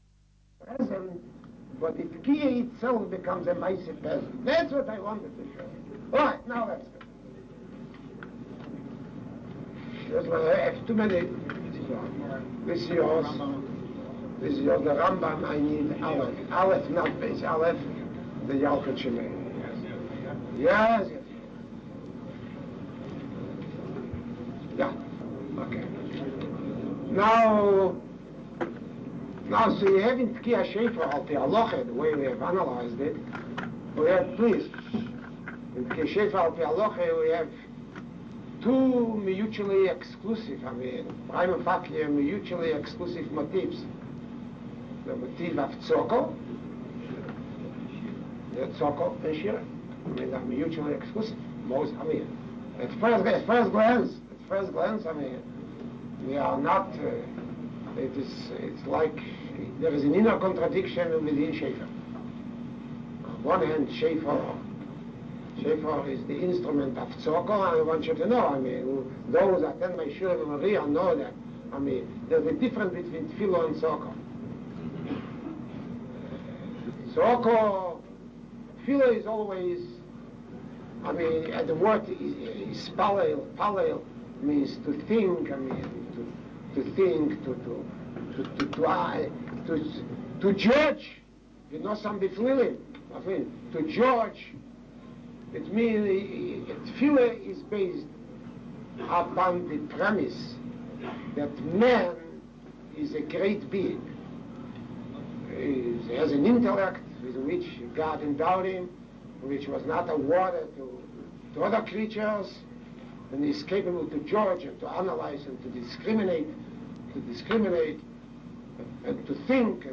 Shiurim of Rav Joseph Soloveitchik
YarcheKallah1972ShofarandRoshHashana.mp3